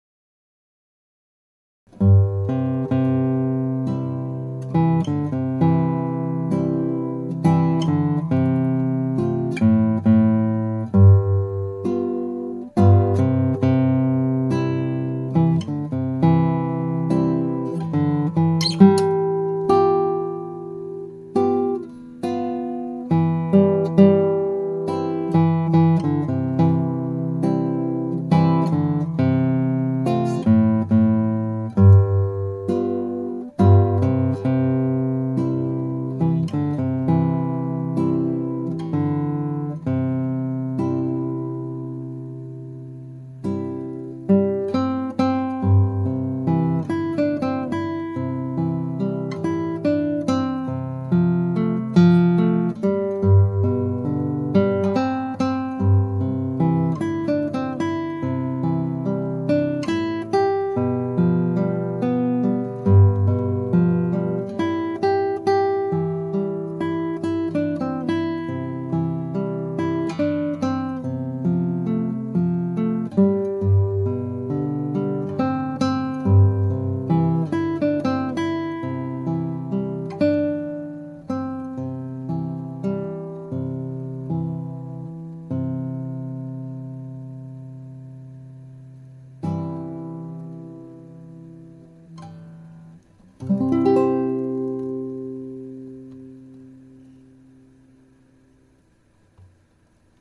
Guitar amatuer play